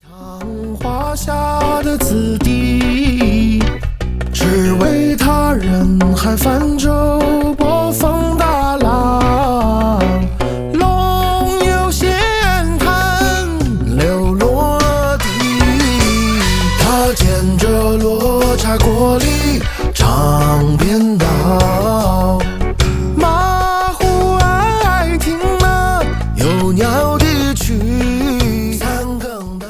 此系列尝试构建流行音乐与民间传统文化共生共存的音乐生态。
十一首原创作品，十一段民间歌曲印象。